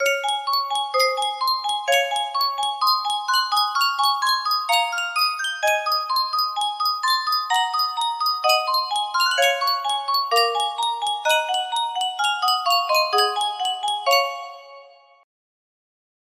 Yunsheng Music Box - Beethoven Piano Sonata No. 8 Op. 13 4178 music box melody
Full range 60